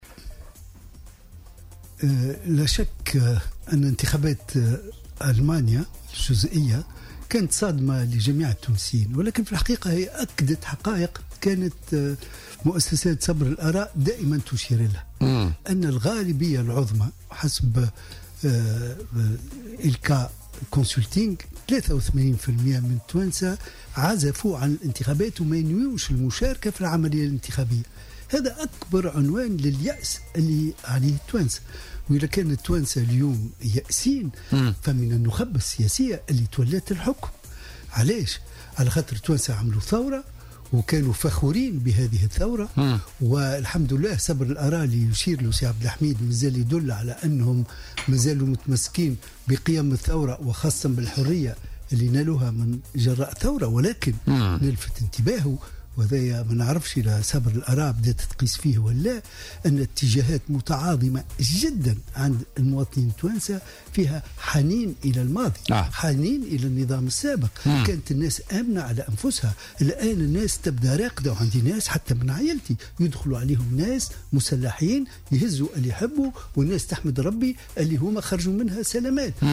وأضاف في مداخلة له اليوم في برنامج "بوليتيكا" أن " العزوف عن الانتخابات هي أكبر عنوان لليأس الذي أصبح يعاني منه الشعب التونسي".